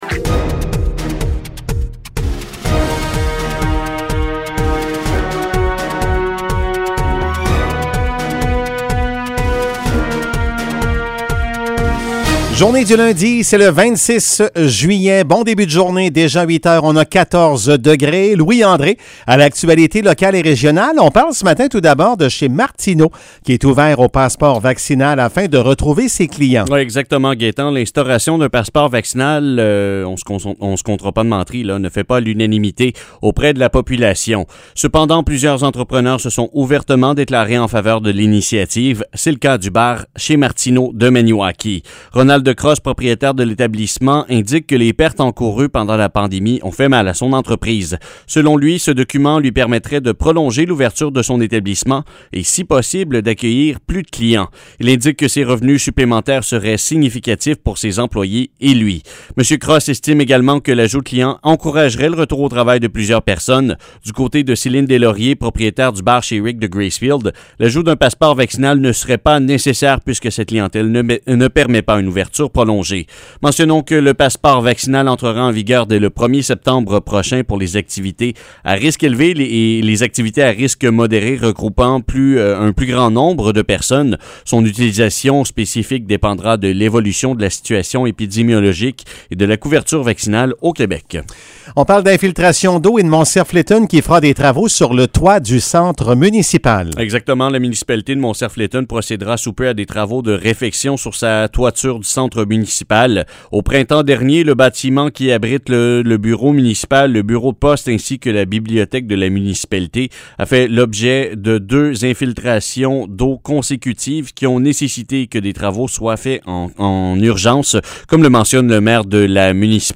Nouvelles locales - 26 juillet 2021 - 8 h